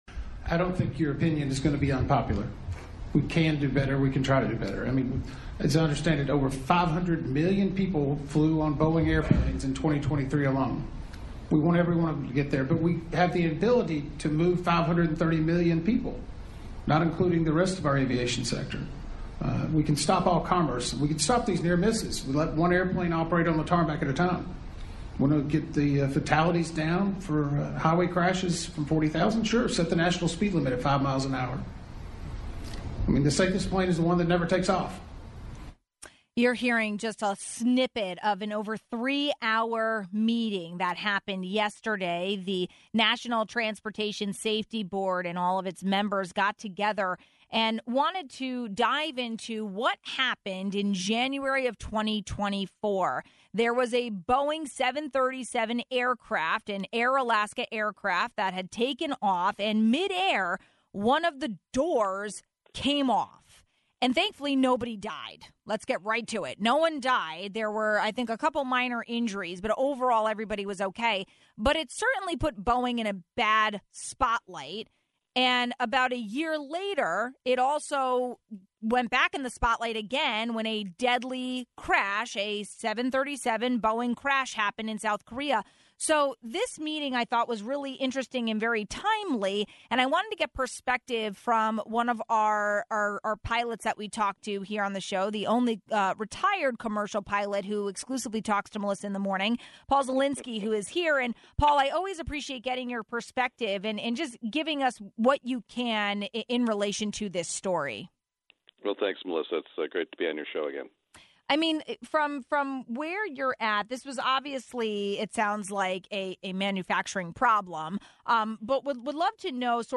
What went wrong and how can the problem be avoided in future? We got perspective from retired airline pilot